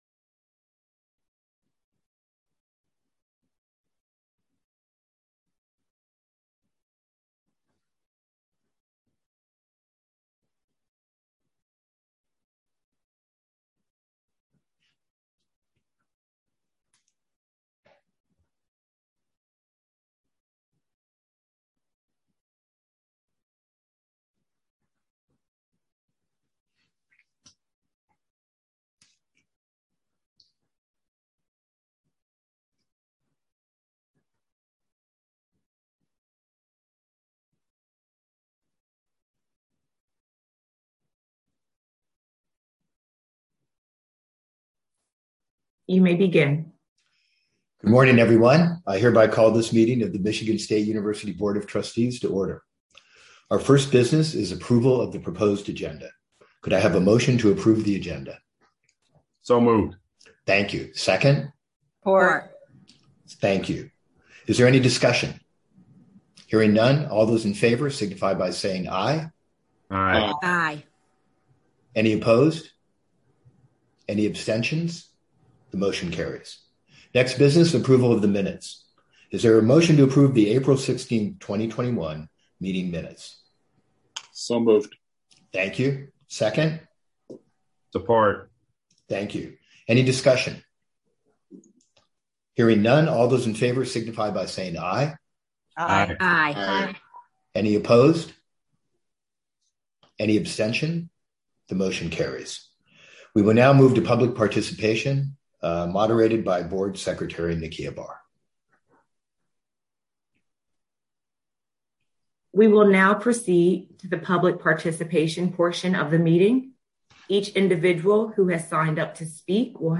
Where: Via Zoom